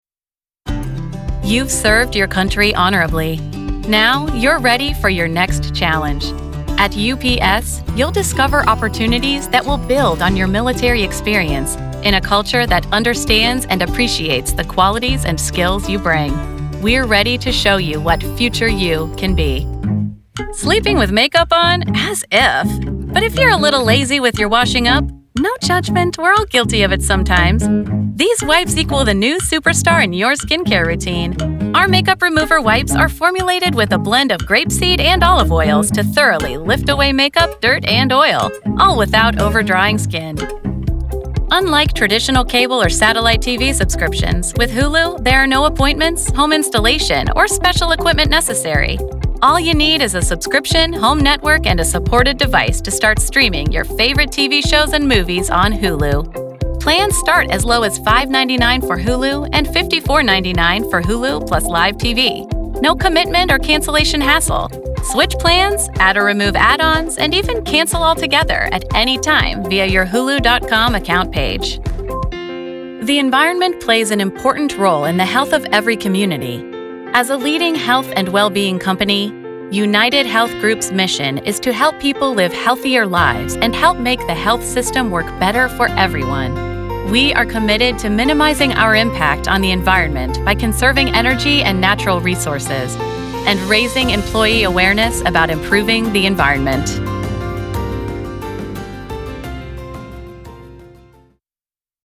Corporate Narration